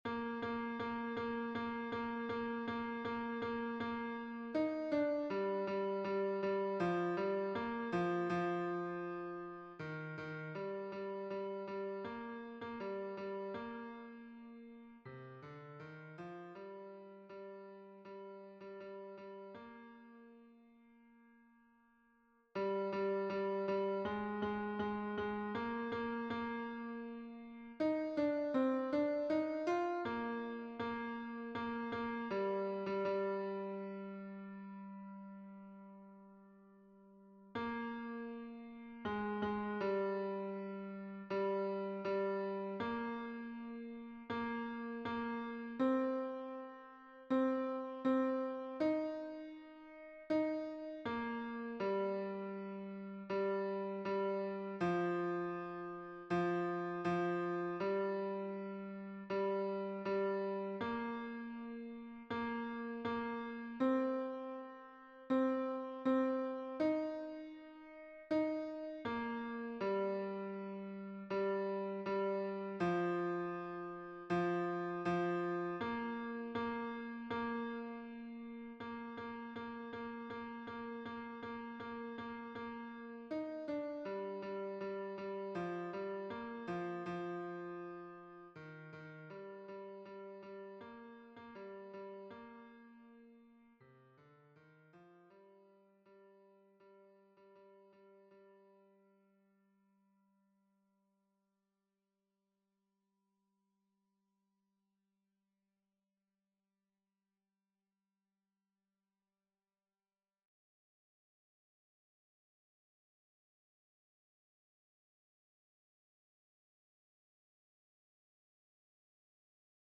MP3 version piano
Tenor 2